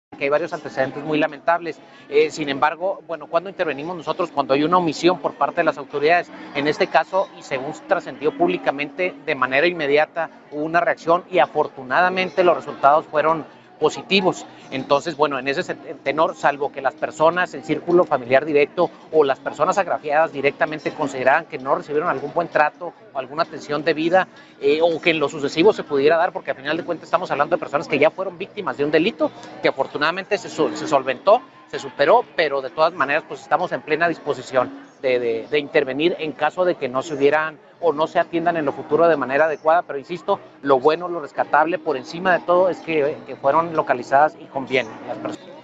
AUDIO: NÉSTOR ARMENDÁRIZ, PRESIDENTE DE LA COMISIÓN ESTATAL DE DERECHOS HUMANOS (CEDH)